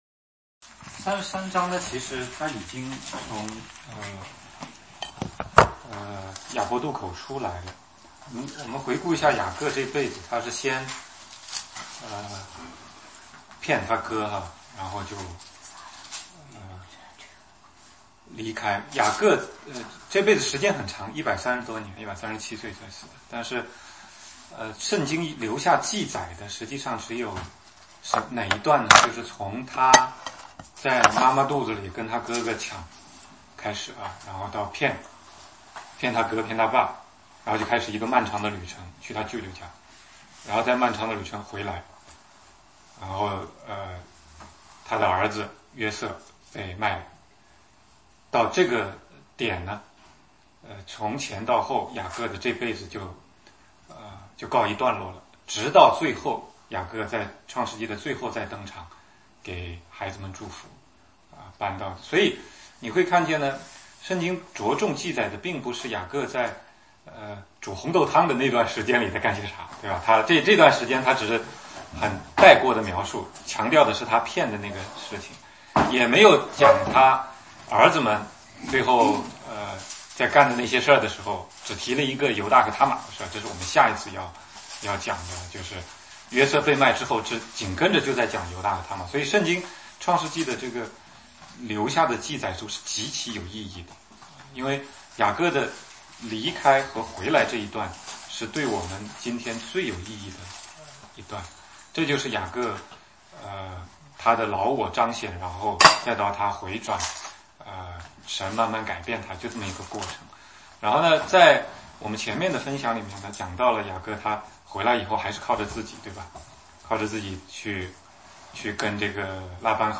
16街讲道录音 - 底拿受辱